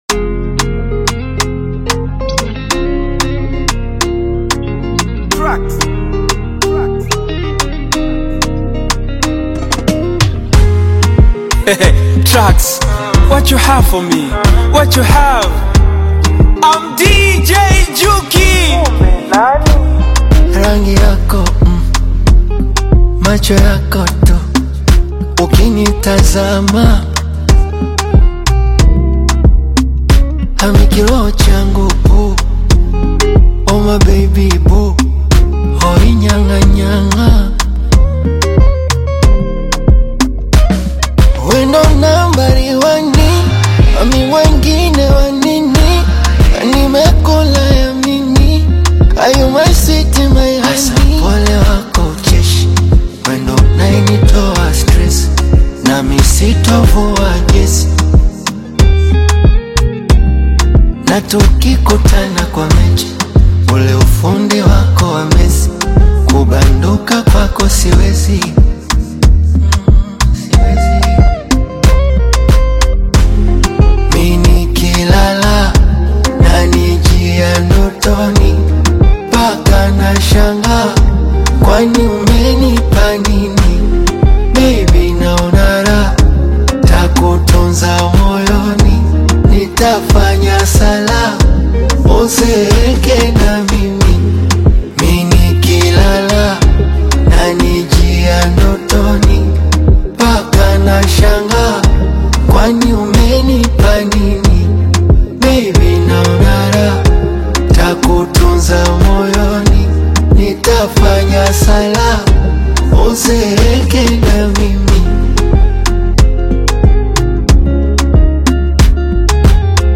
soulful Bongo Flava single
and emotion with modern Afrobeat-inspired rhythms.
emotive vocal delivery
catchy melodies and relatable lyrics